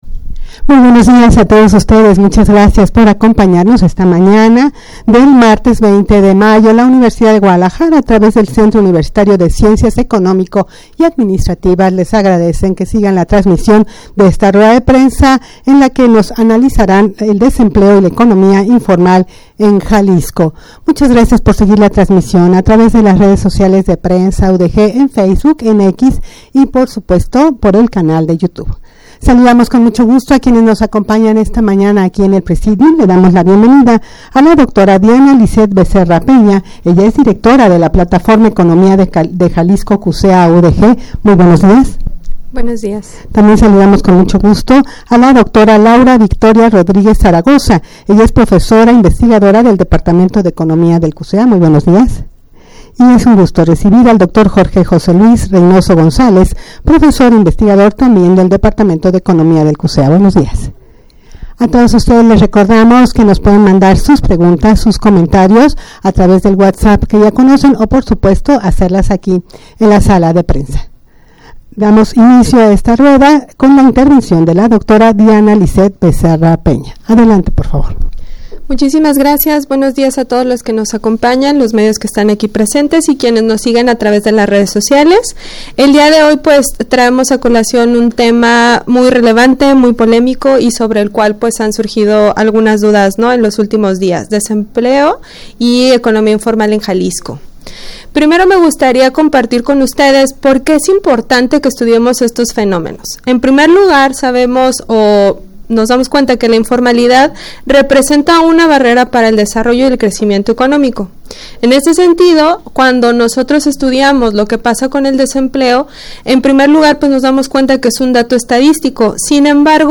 rueda-de-prensa-22desempleo-y-economia-informal-en-jalisco22.mp3